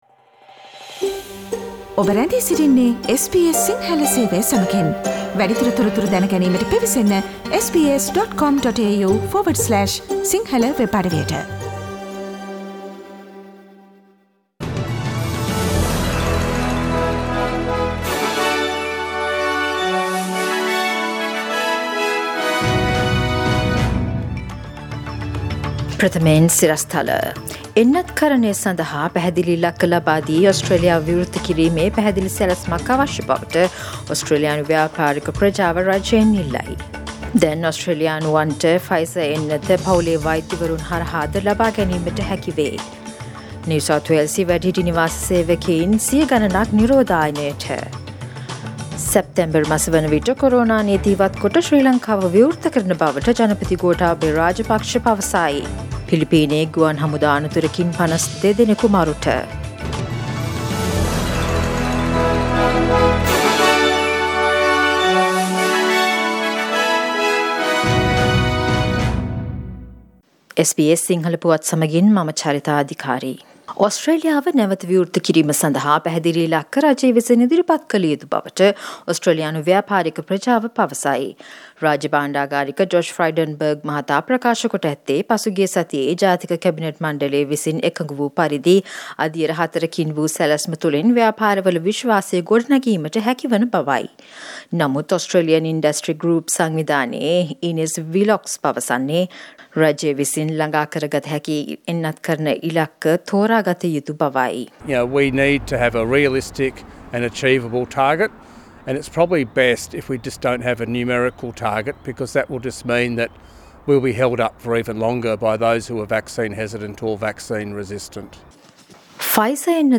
SBS Sinhala radio news 6 July 2021:Business groups demand clear vaccination targets to accelerate recovery